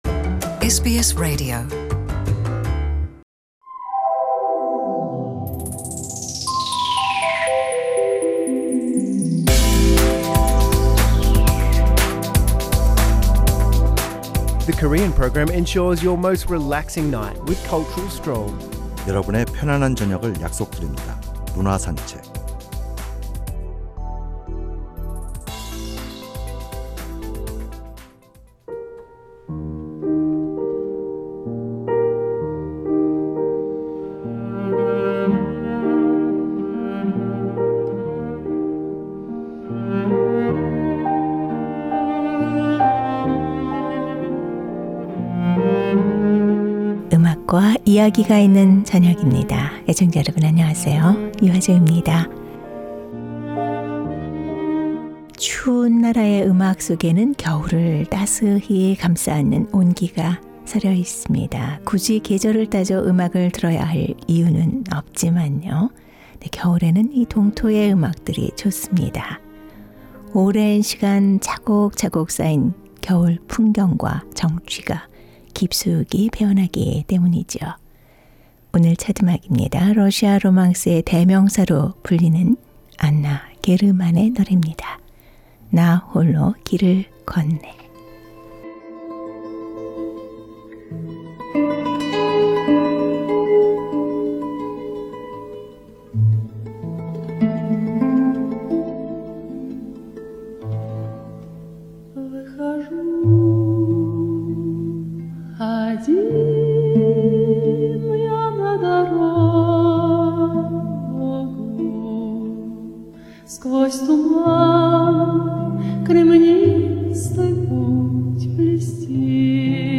섬세한 감정 표현과 꾸밈없이 청아한 목소리가 일품으로 로망스계의 신데렐라로 불립니다.